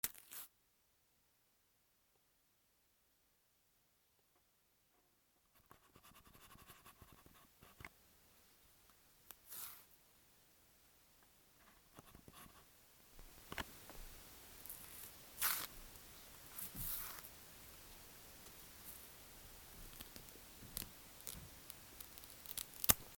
バナナの皮をむく
『シュ』